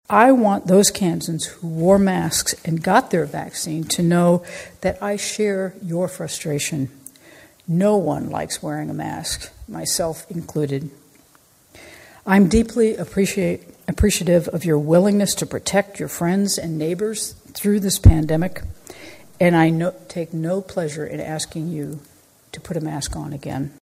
Kansas Governor Laura Kelly speaks during a news conference Tuesday on updated COVID-19 mask policy at the state level.